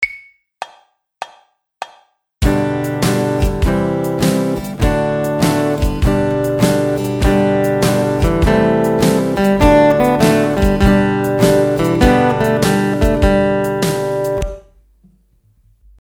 They both play a similar lick over two different sets of chord changes in the key of G major then in E minor. You will notice that the major one sounds like country music while the minor one will sound more like the blues.
G Major Pentatonic Lick | Download
gmaj_pent_lick.mp3